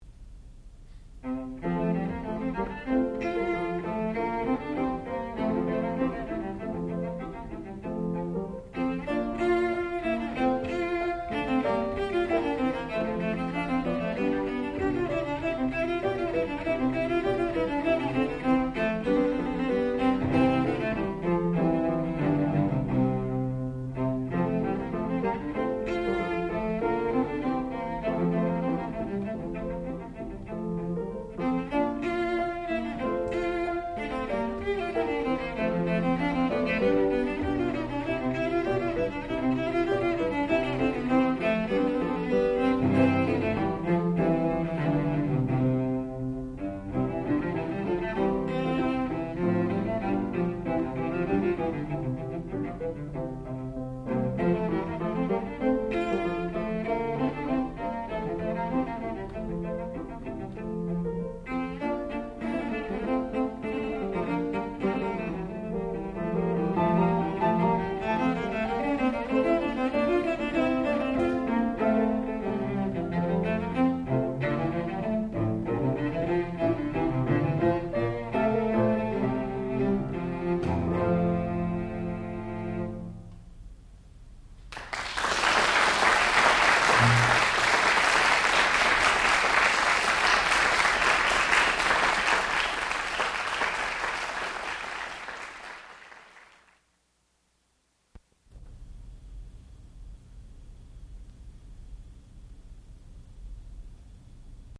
sound recording-musical
classical music
violoncello
piano